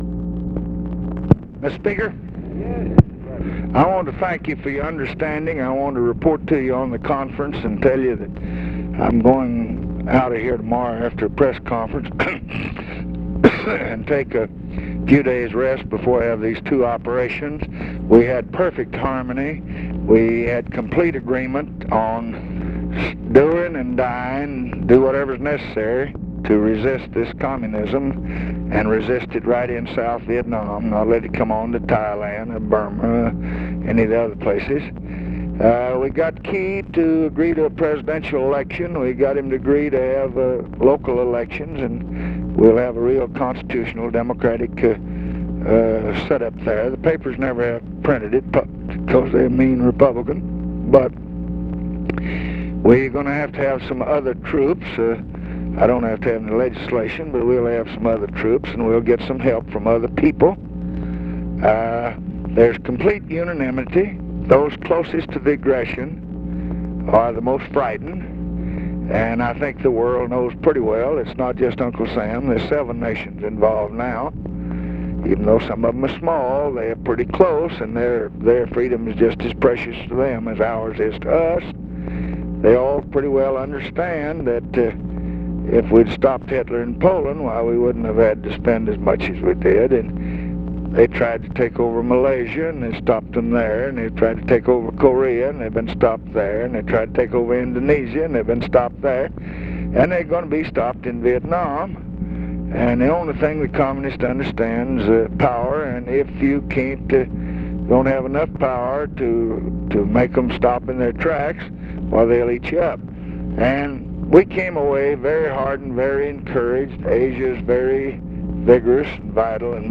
Conversation with JOHN MCCORMACK, November 3, 1966
Secret White House Tapes